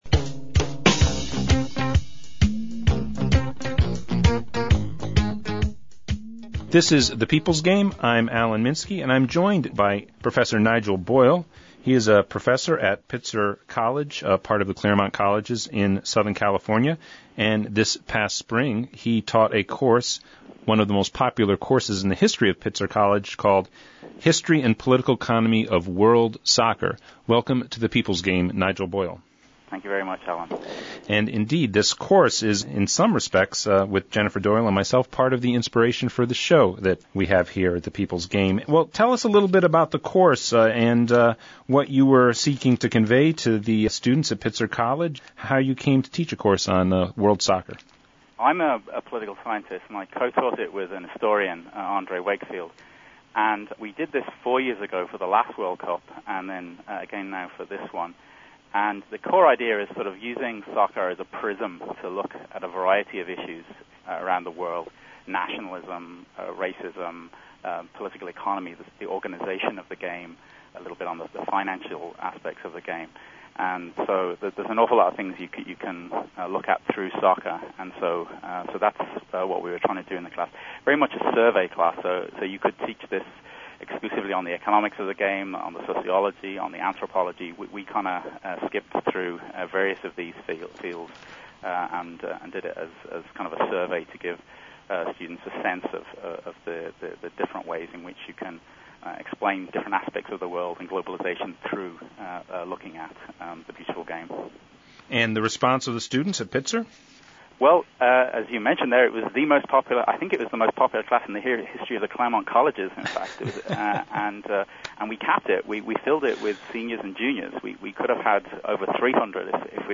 Today’s show features an interview